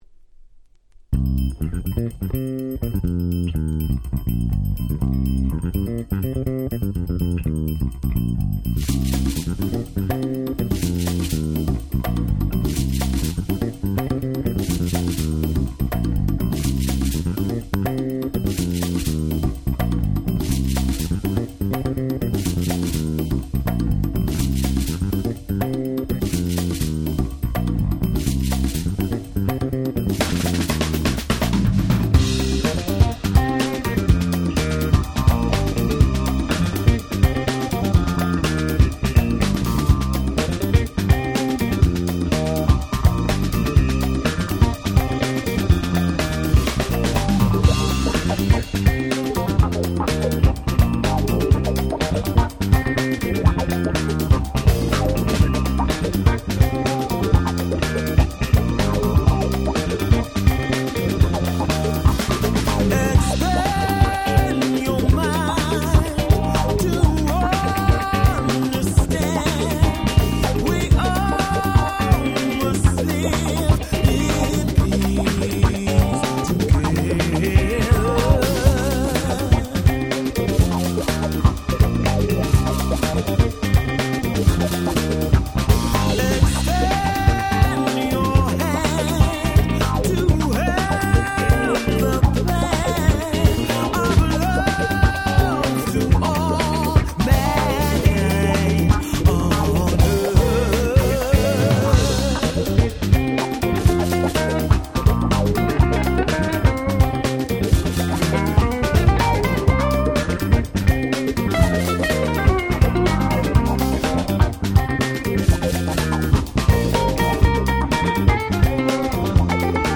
22' Very Nice Acid Jazz !!